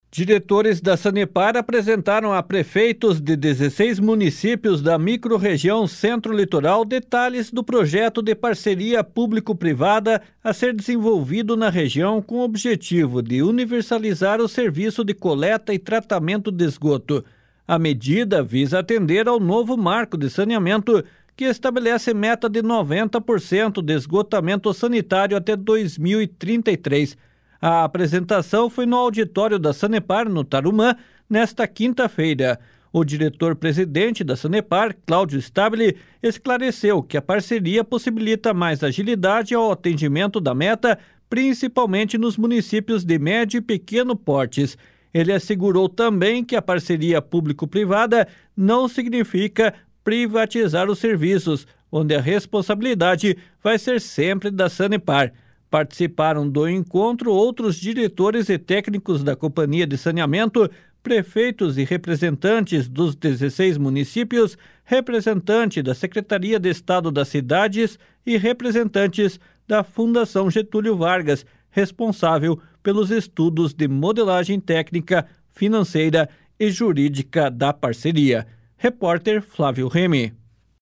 A medida visa atender ao novo marco de saneamento que estabelece meta de 90% de esgotamento sanitário até 2033. A apresentação foi no auditório da Sanepar, no Tarumã, nesta quinta-feira.